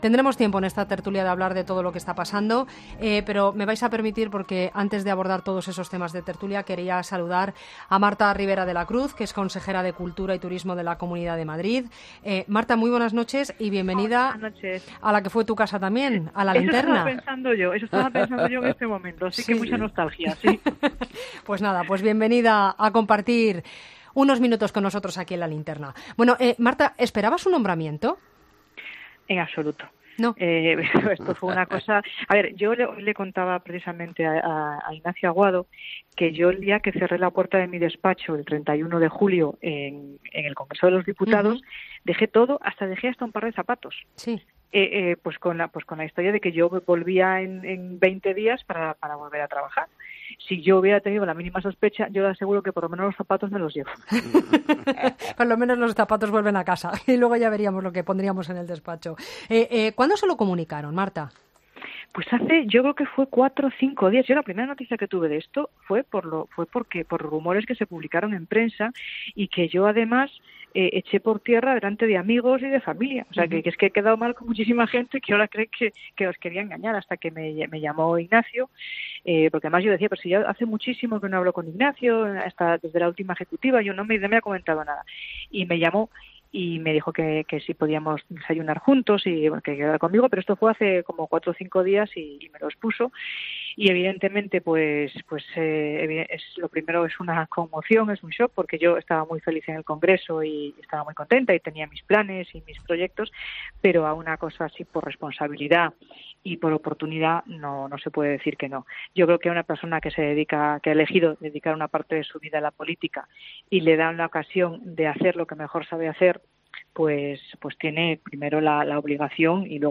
La nueva consejera de Cultura y Turismo de la Comunidad de Madrid ha comentado en la Cadena COPE la actualidad política de la Comunidad de Madrid